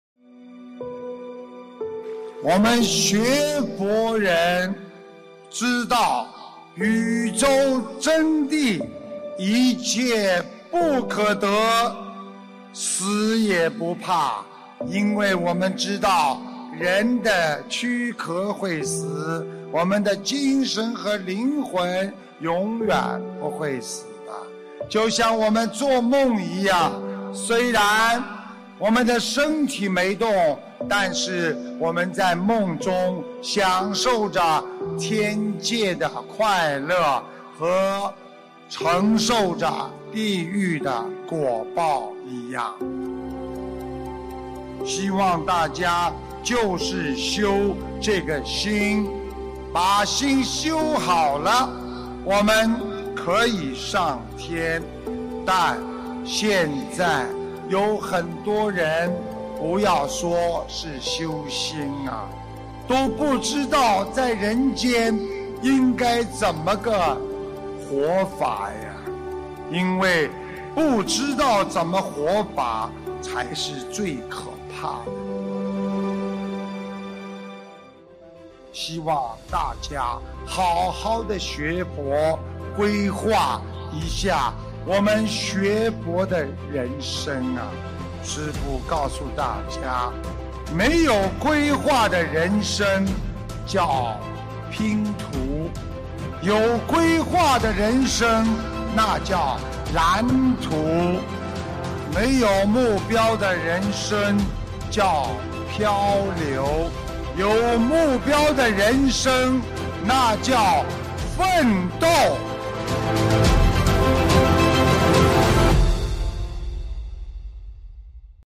—— 2015年1月24日 马来西亚 槟城法会开示